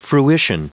Prononciation du mot fruition en anglais (fichier audio)
Prononciation du mot : fruition